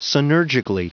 Prononciation du mot synergically en anglais (fichier audio)
Prononciation du mot : synergically